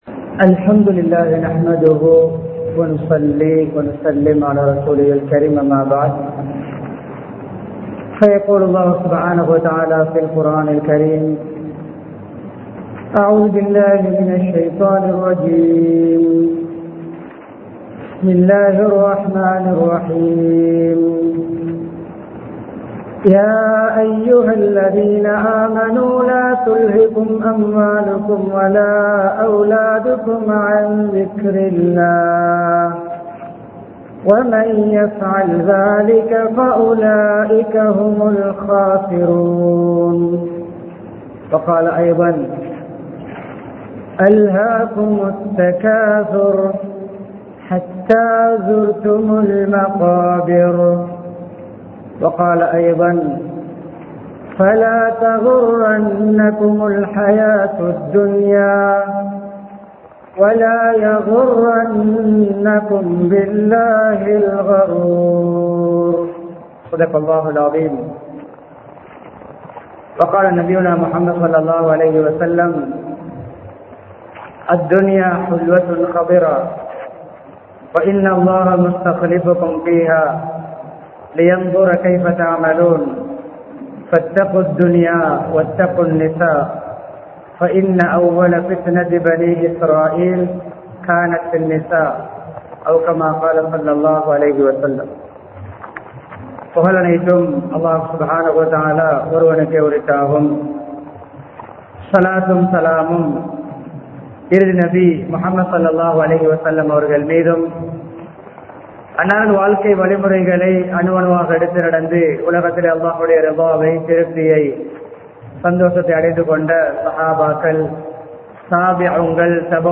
உலக வாழ்வின் ஏமாற்றம் | Audio Bayans | All Ceylon Muslim Youth Community | Addalaichenai
Al Ibrahimiya Jumua Masjidh